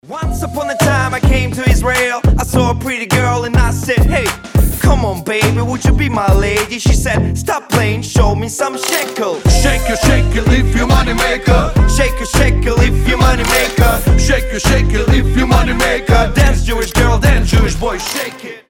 • Качество: 320, Stereo
ритмичные
Хип-хоп